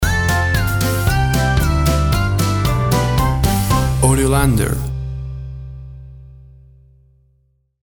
Upbeat, uptempo and exciting!.
Tempo (BPM) 114